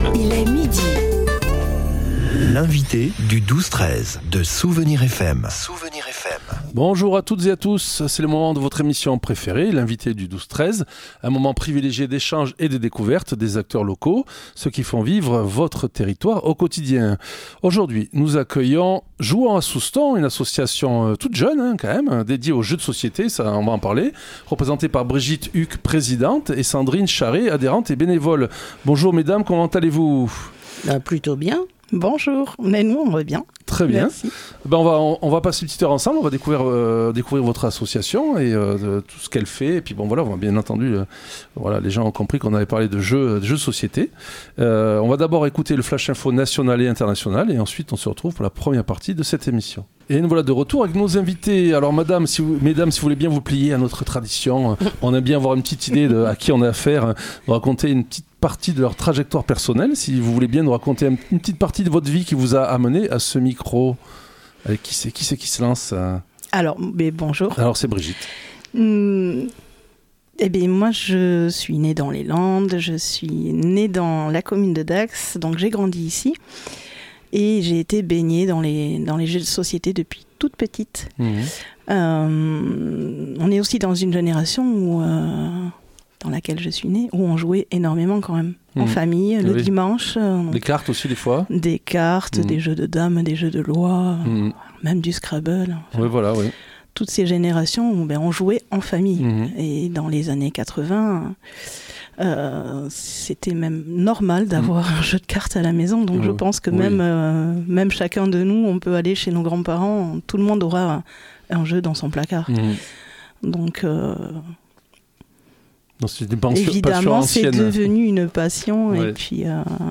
Nos invitées nous ont présenté leur jeune association, créée en mai , dont la raison d’être est le jeu de société, mais surtout le lien social et fraternel qu’il génère. Leur objectif ? Créer du lien, et plus particulièrement intergénérationnel, à travers des moments de partage et de convivialité.